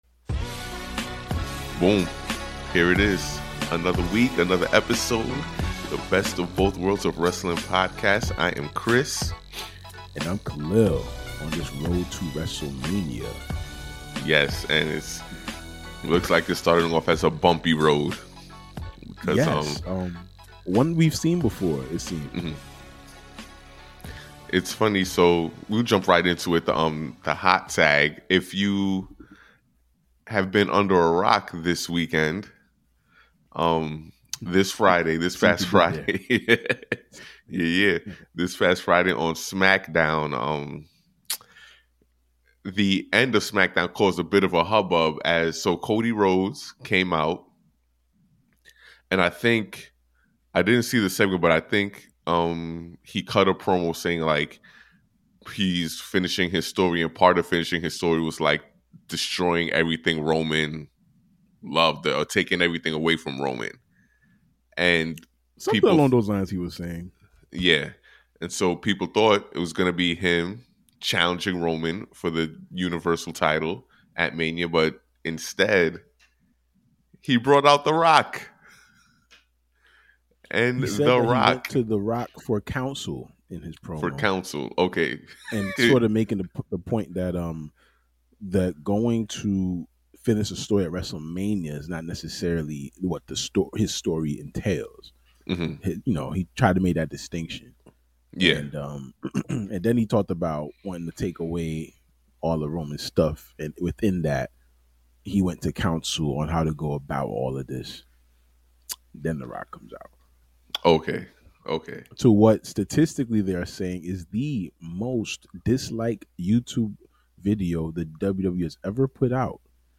A unique take from two homies from Brooklyn that grew up loving the art of professional wrestling while still being tapped in into the culture at large. This isn't has-beens yelling at clouds to get off of their lawn, nor is it the basement-dwelling neckbeards spewing toxic hot takes. It's barbershop talk for wrestling, and some damn good barbershop talk at that!